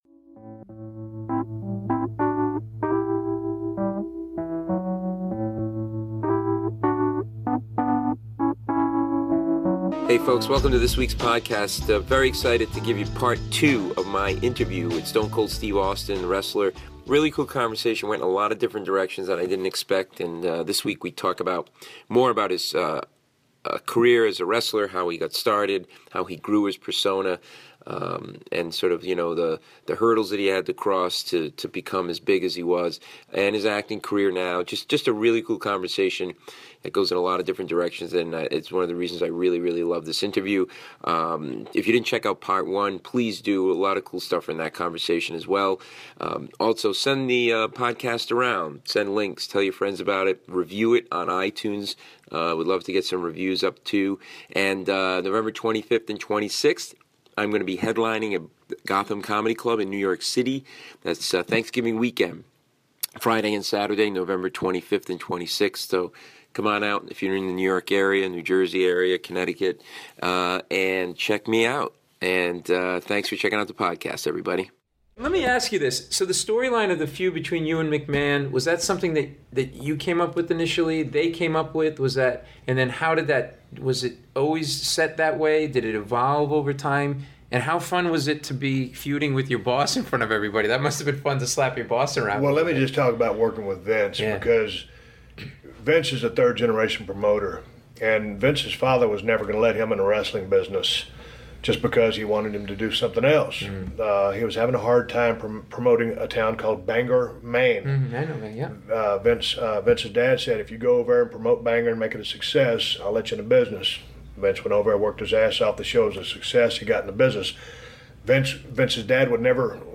This is an eye-opening interview that I REALLY love because it took me down roads I didn't think we would go.